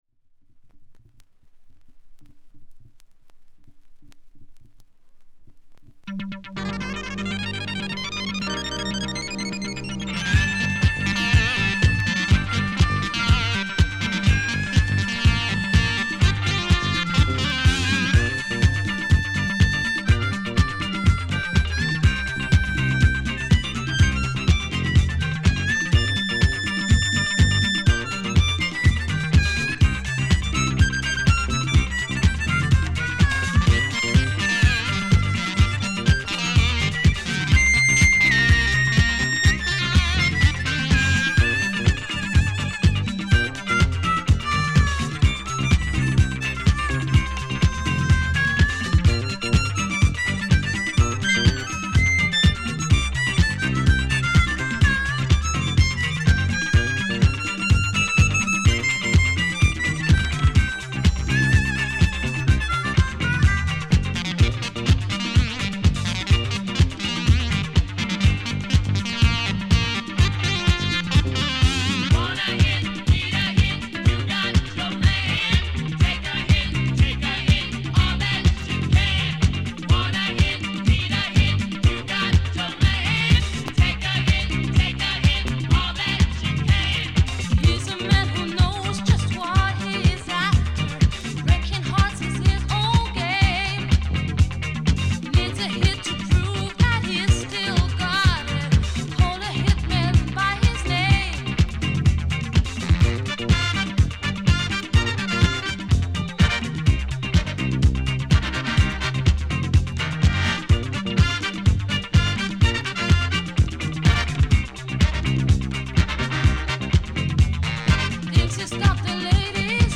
フレンチ・ヴォーカリスト